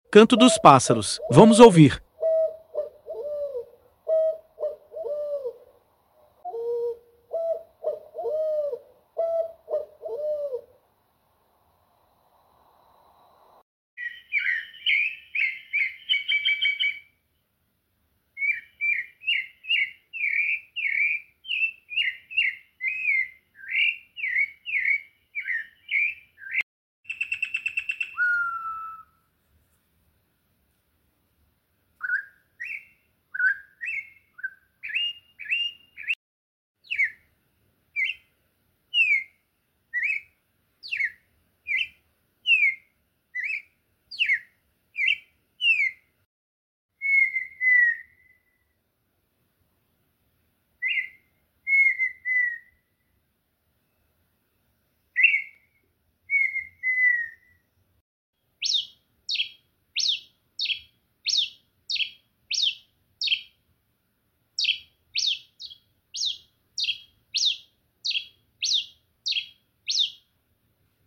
canto dos pássaros mais lindo sound effects free download